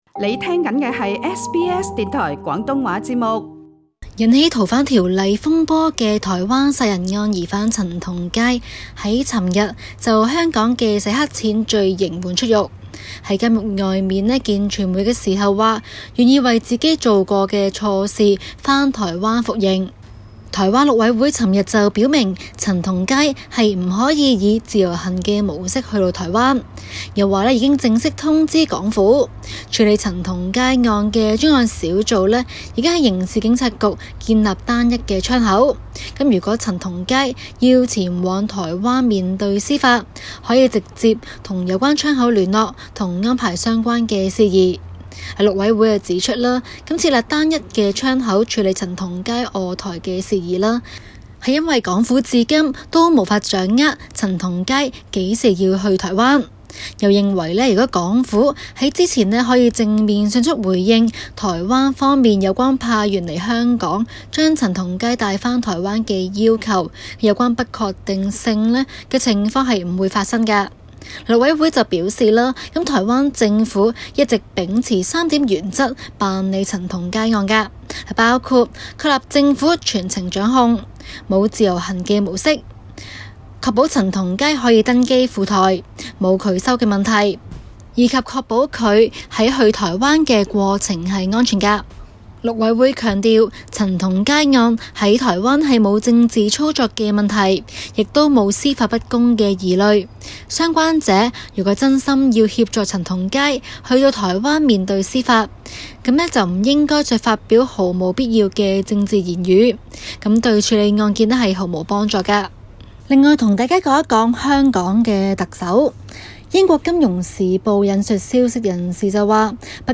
SBS Cantonese